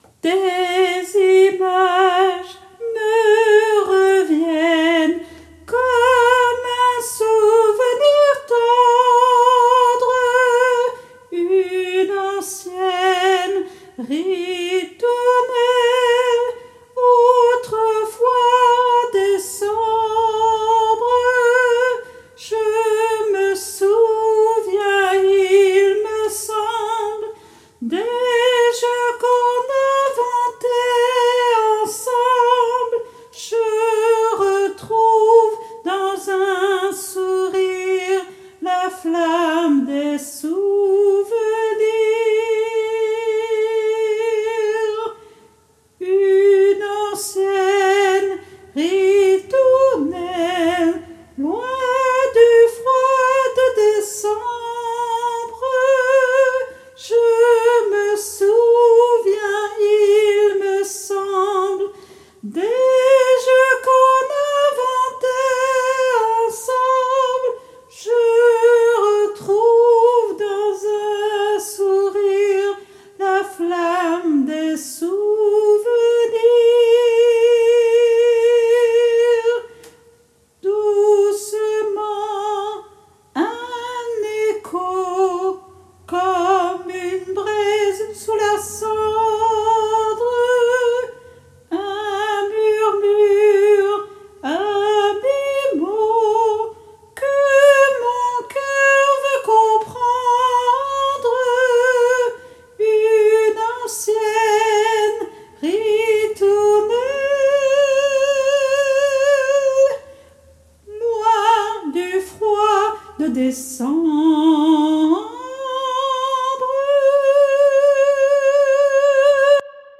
MP3 versions chantées
Soprano